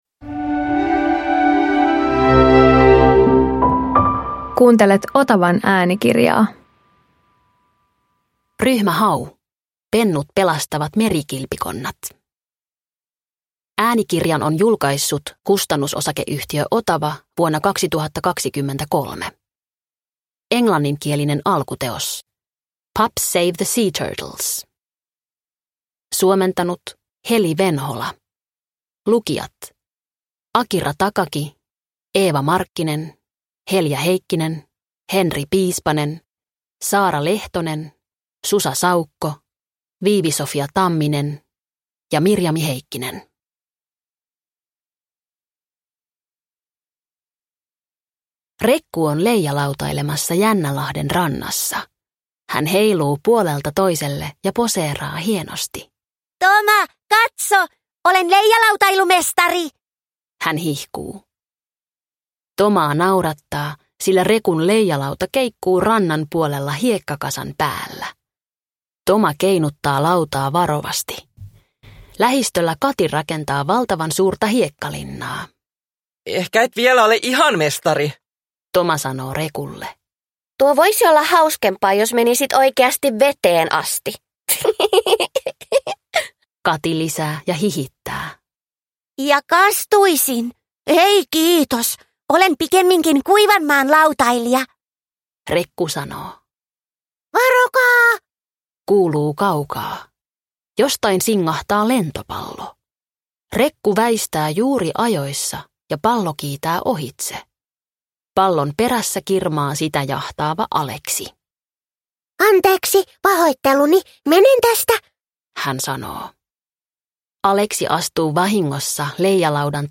Ryhmä Hau - Pennut pelastavat merikilpikonnat – Ljudbok – Laddas ner